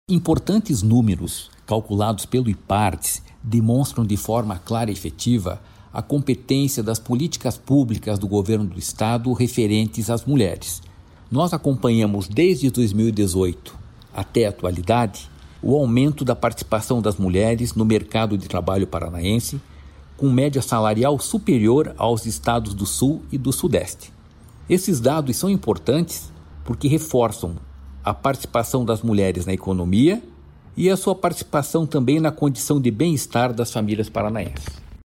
Sonora do presidente do Ipardes, Jorge Callado, sobre o aumento no salário das mulheres no Paraná